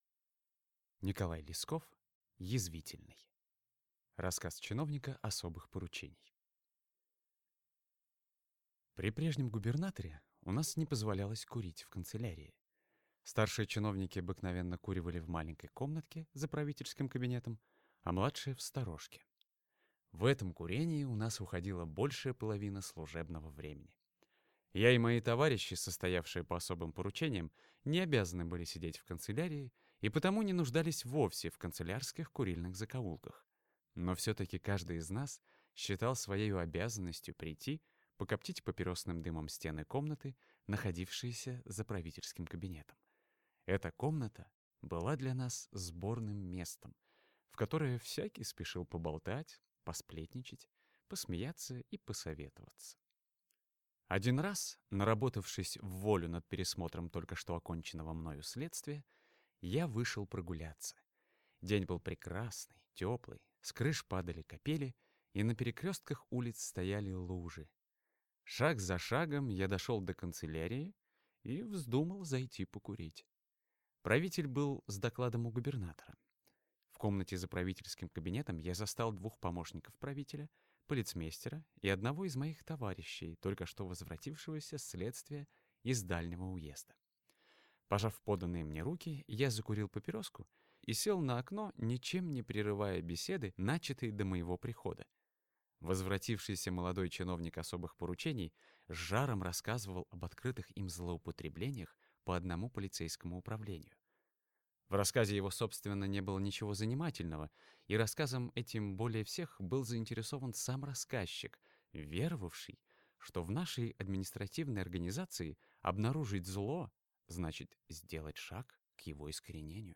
Аудиокнига Язвительный | Библиотека аудиокниг